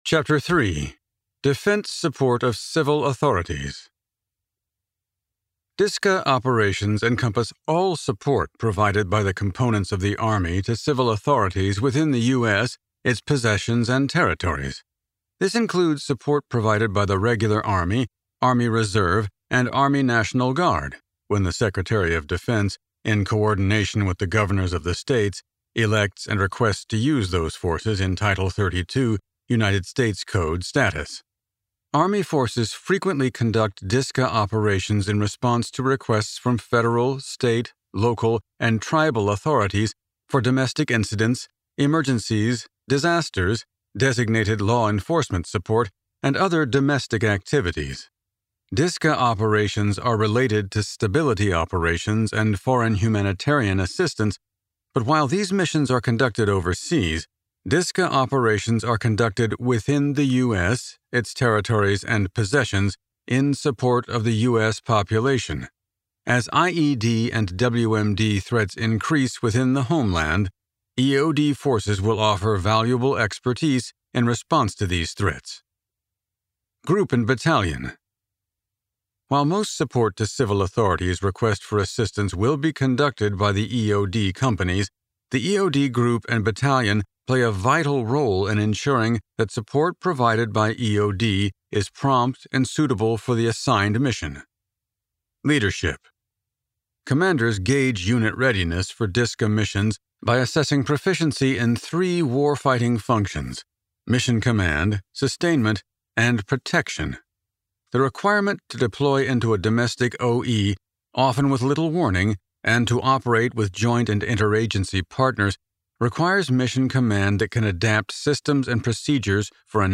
Army Doctrine Audiobook Download Page
It has been abridged to meet the requirements of the audiobook format.